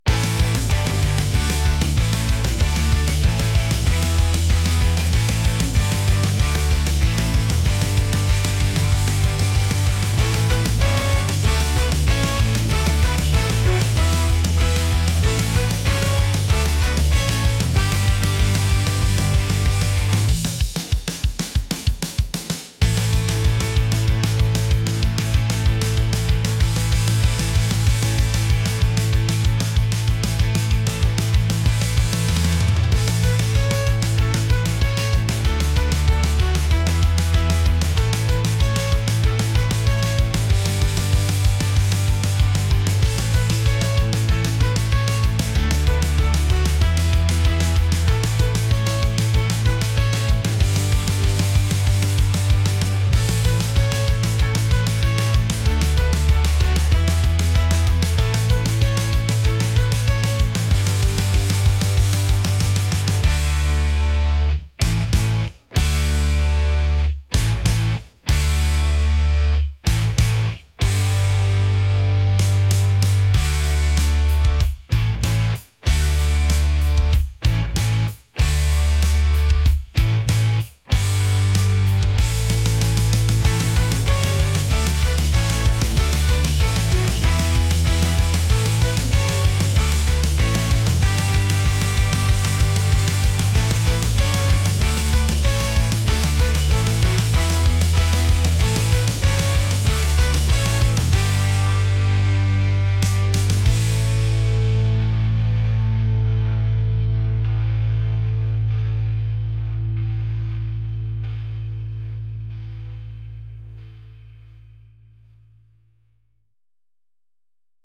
energetic | ska | punk